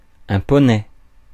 Ääntäminen
Ääntäminen France: IPA: [œ̃ pɔ.nɛ] Tuntematon aksentti: IPA: /pɔ.nɛ/ Haettu sana löytyi näillä lähdekielillä: ranska Käännös 1. poni Suku: m .